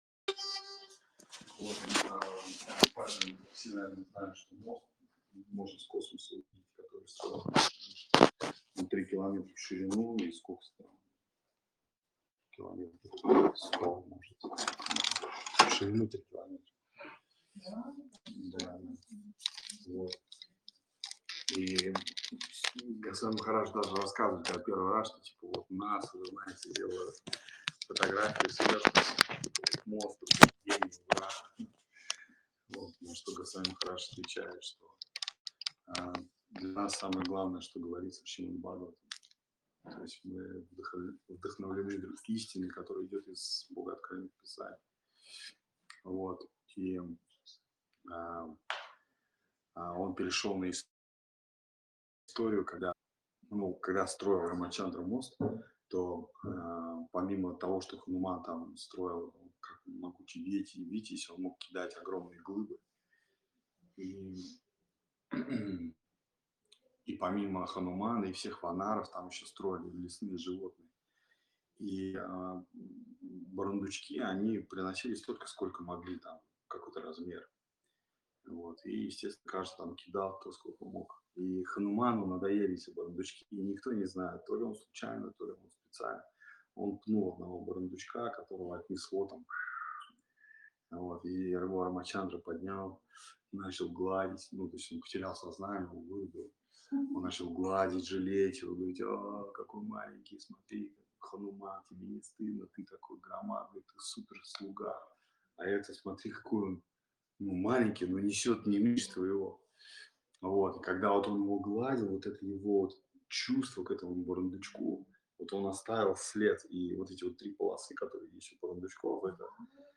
Место: Кисельный (Москва)
Лекции полностью
Бхаджан